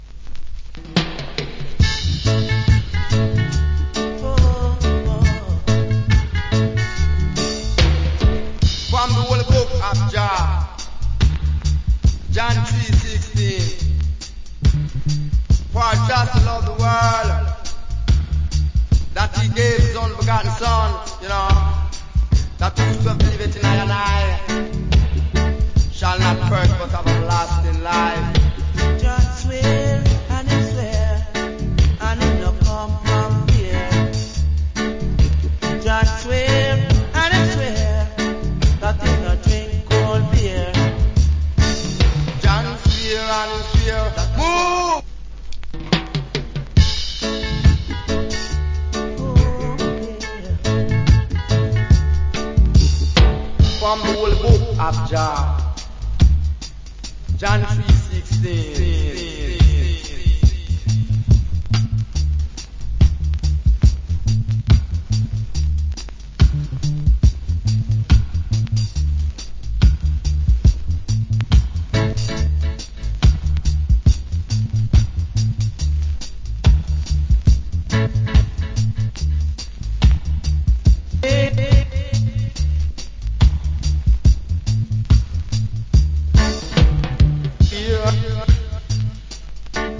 Good DJ.